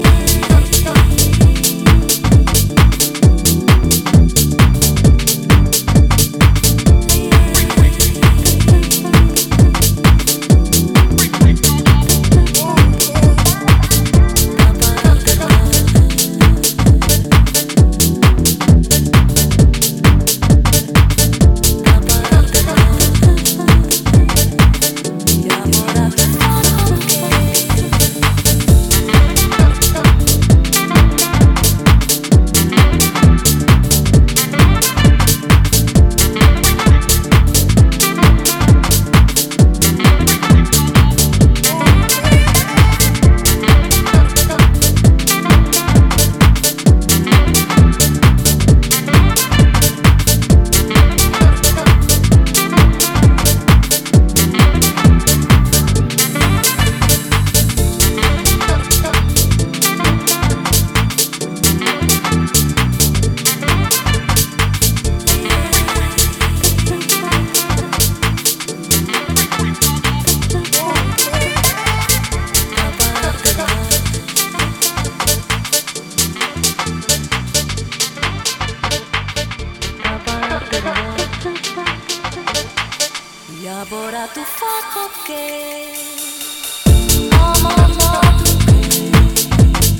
four-to-the-floor club music
luscious chords, sultry rhythms and resonant sax-riffs